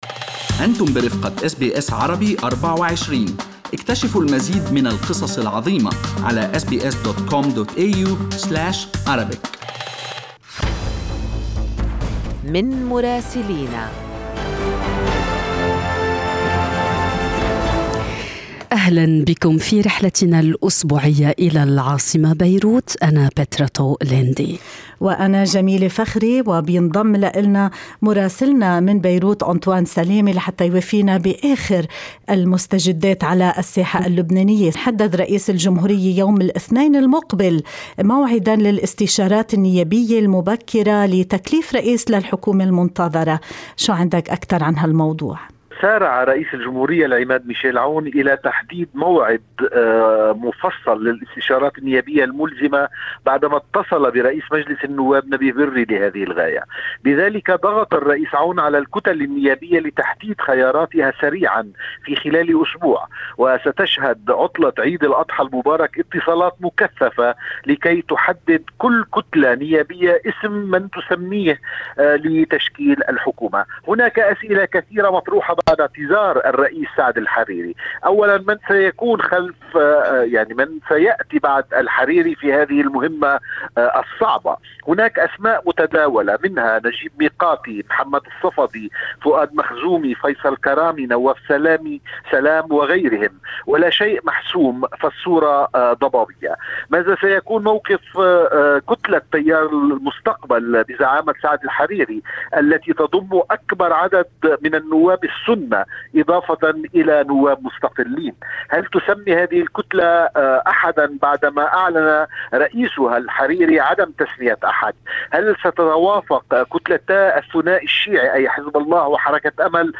من مراسلينا: أخبار لبنان في أسبوع 20/7/2021